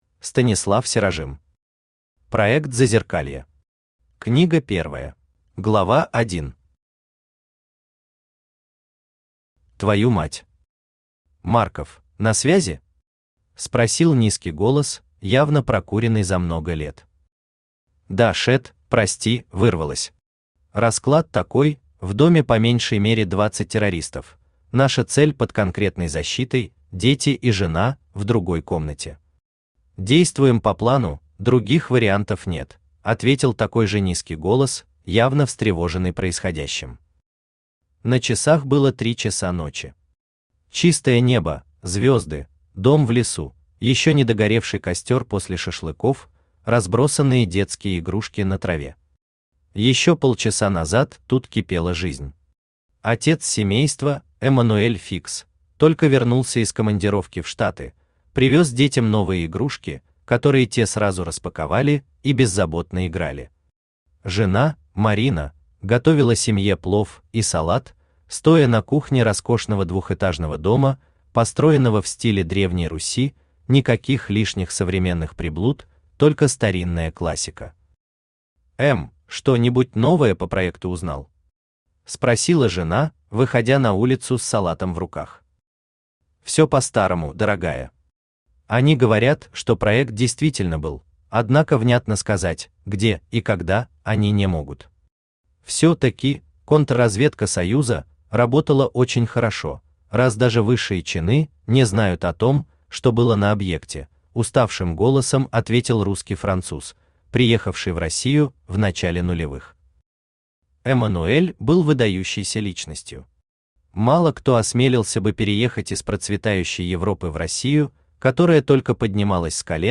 Книга первая Автор Станислав Серажим Читает аудиокнигу Авточтец ЛитРес.